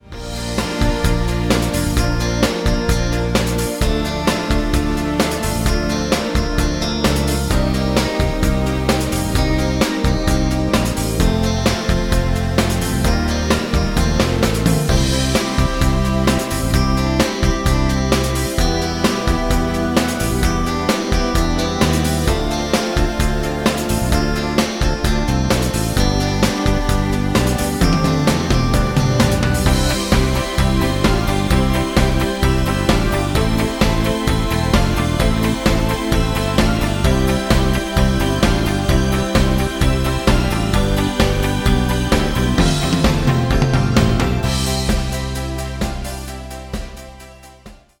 karaoke, strumentale